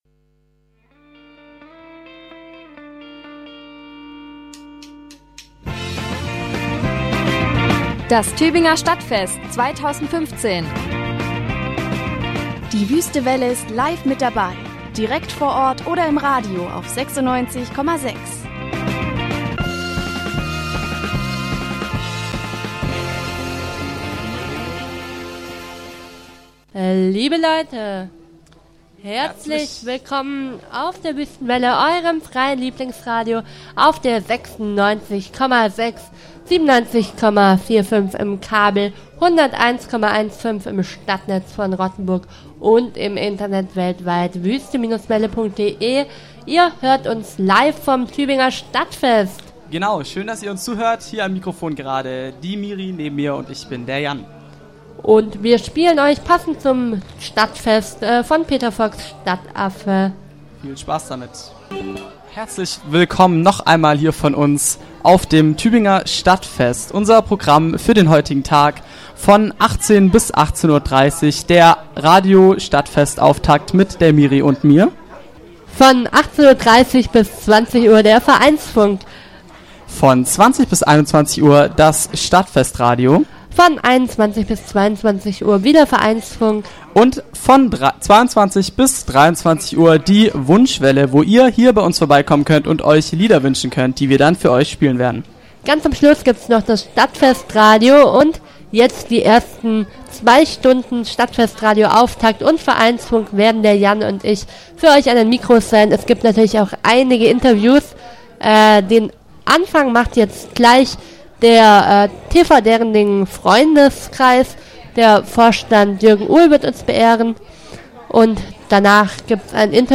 Die Wüste Welle hat mit einem umfassenden Programm und einer Liveübertragung über das Stadtfest in Tübingen berichtet.
Die beiden Interviews wurden am ersten Tag des Stadtfestes, am Freitag den 10. Juli 2015, aufgenommen.